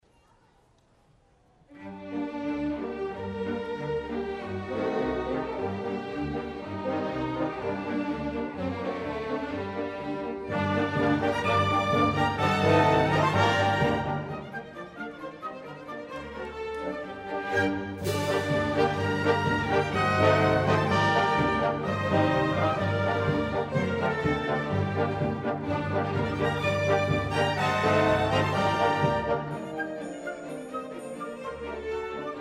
Instrumental Song Download
Downloadable Instrumental Track